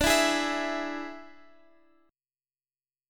Dsus2b5 chord